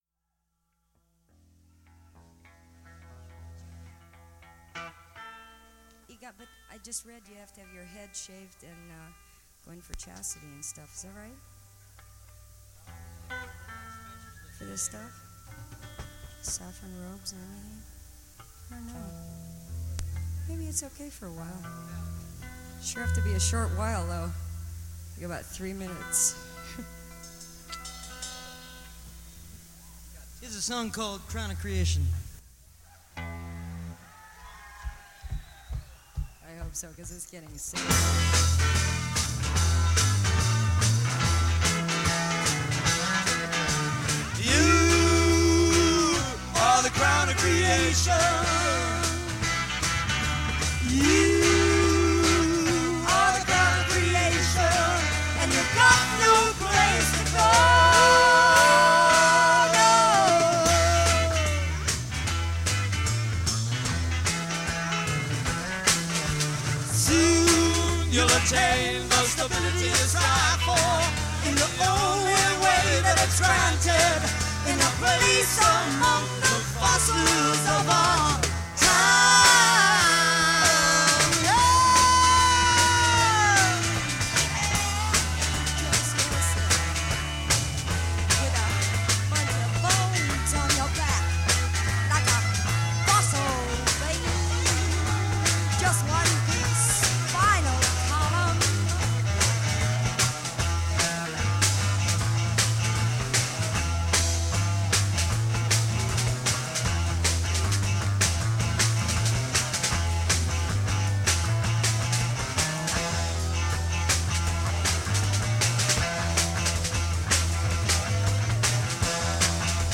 live at Winterland